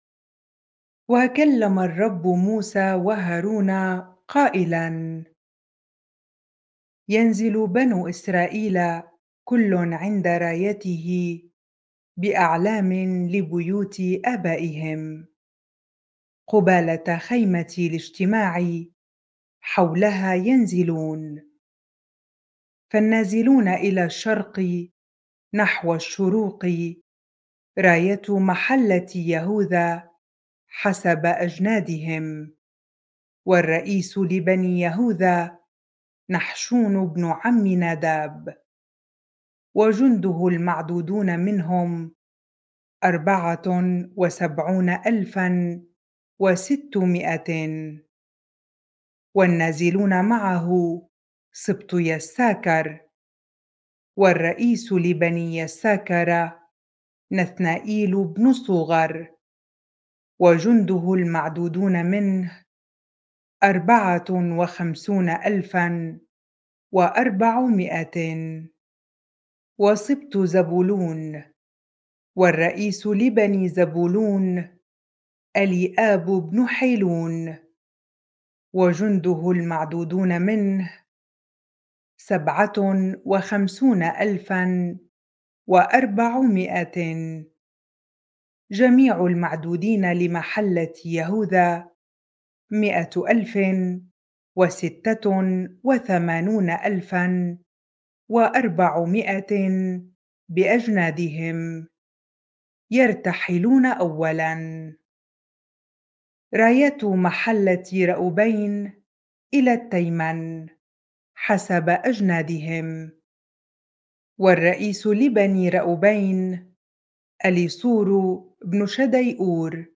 bible-reading-numbers 2 ar